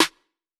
Snares
JJSnares (2).wav